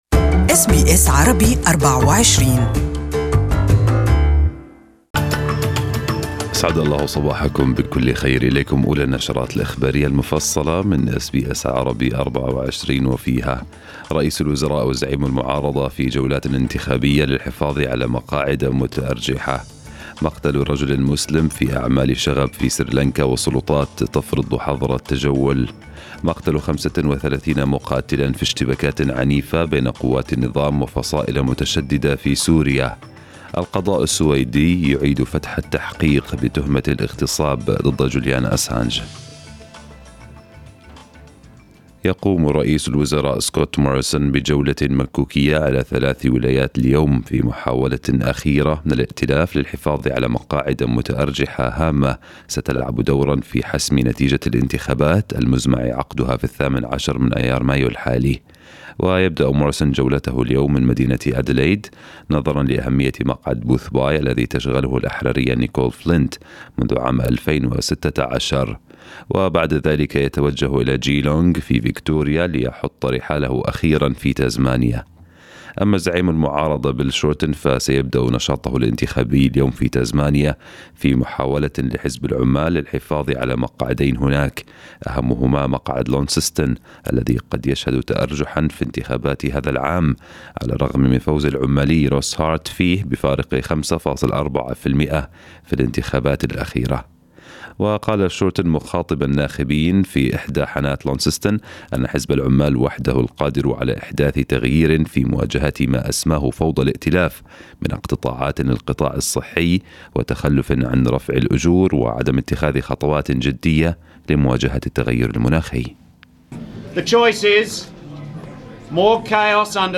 News bulletin in Arabic for the day